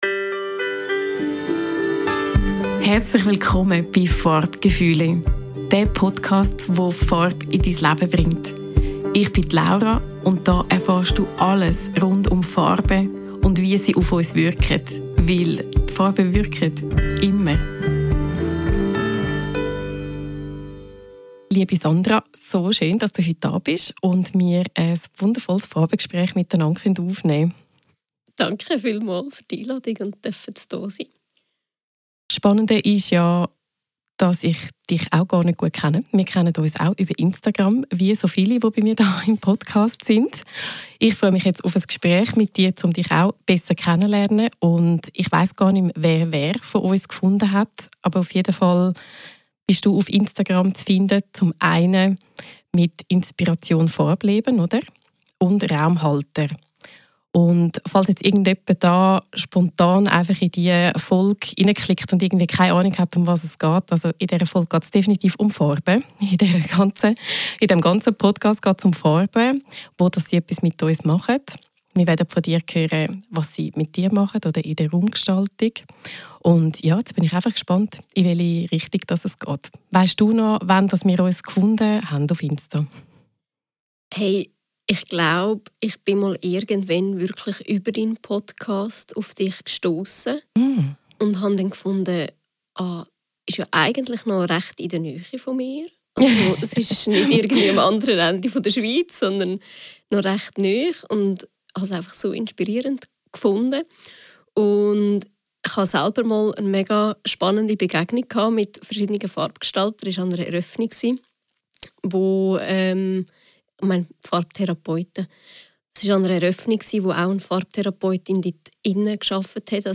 #17 Interview mit Malerin und Raumgestalterin